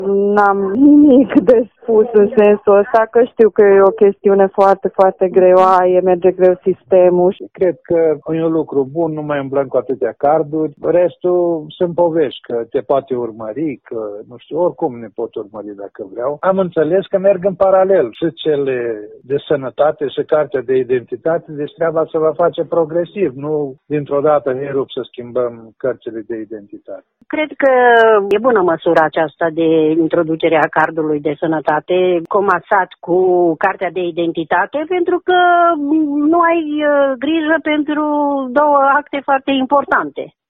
Tîrgumureșenii salută modificarea, pentru că se reduce birocrația și se elimină problemele de funcționalitate a cardului: